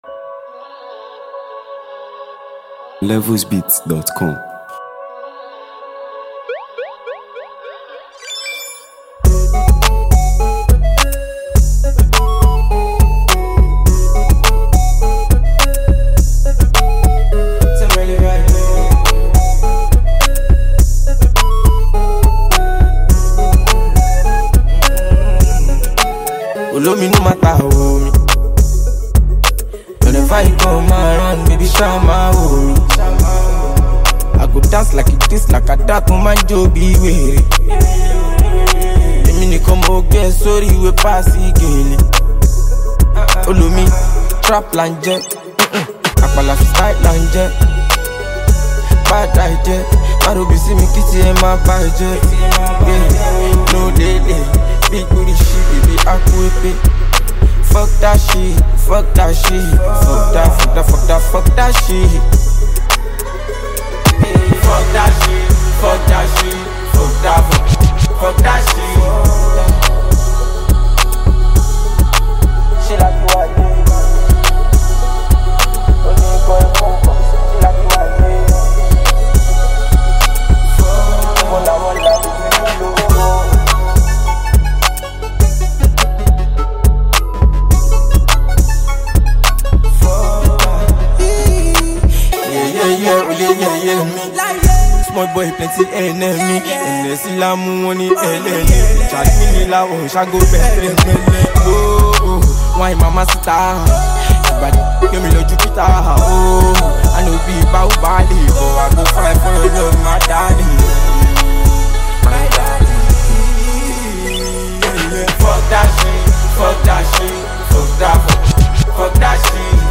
For fans of fresh Afrobeat vibes